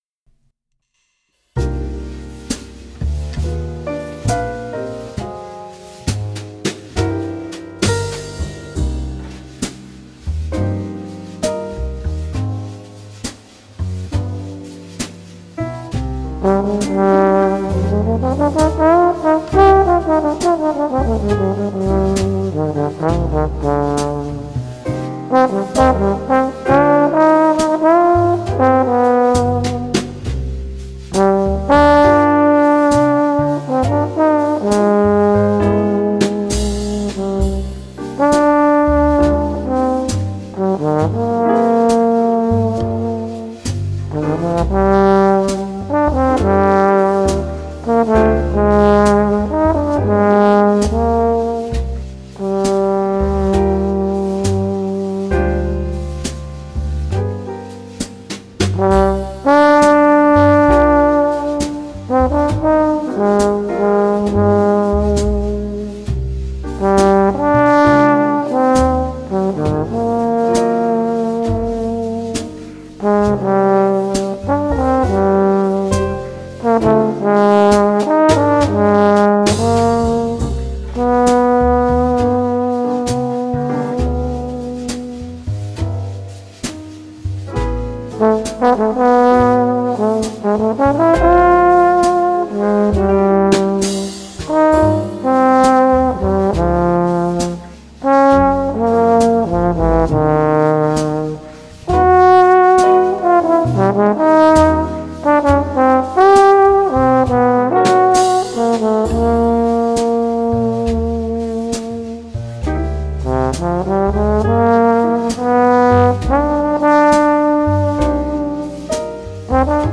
A version transcribed for trombone is -Here-.
a nice down tempo tune